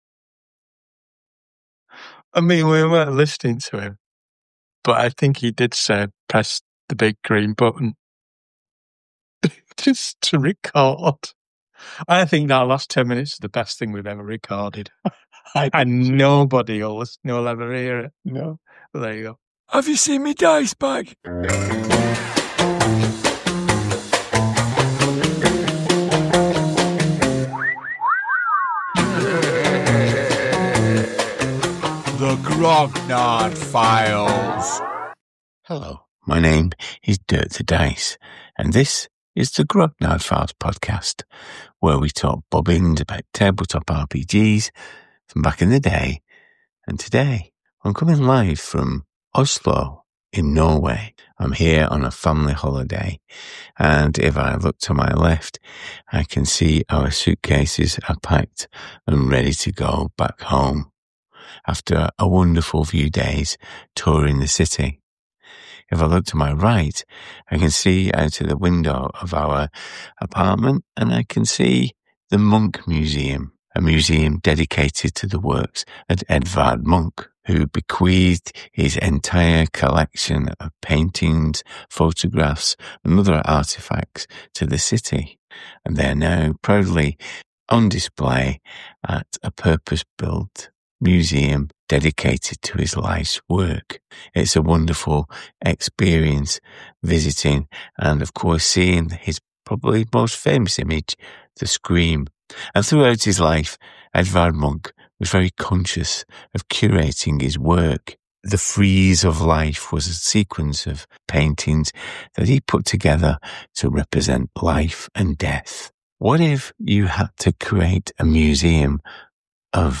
The interview was recorded in front of an audience as part of GROGMEETish in November.